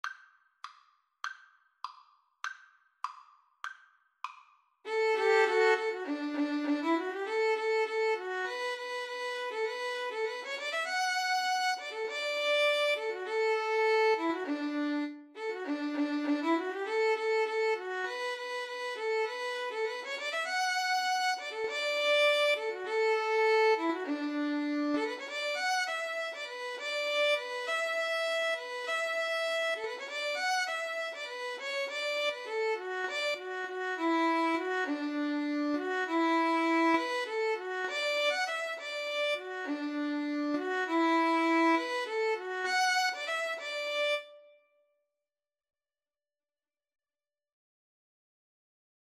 D major (Sounding Pitch) (View more D major Music for Violin-Cello Duet )
2/4 (View more 2/4 Music)
Allegro (View more music marked Allegro)
Violin-Cello Duet  (View more Easy Violin-Cello Duet Music)
Traditional (View more Traditional Violin-Cello Duet Music)